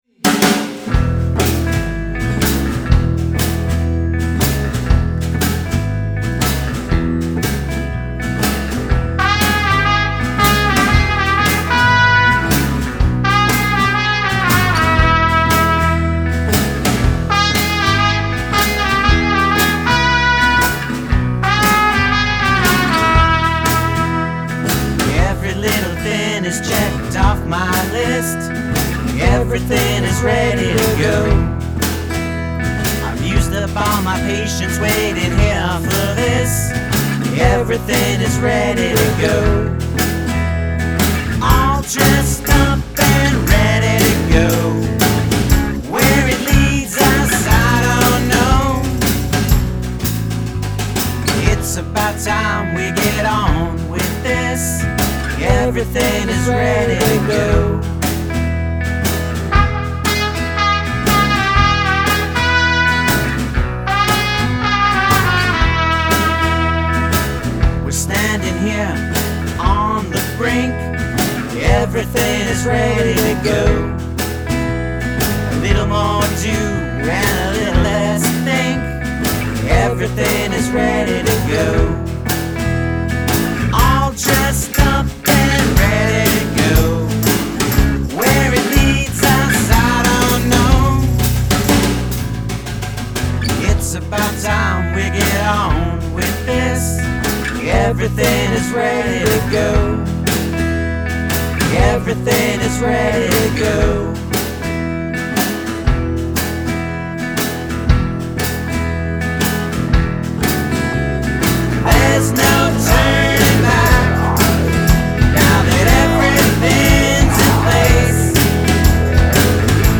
boston's power duo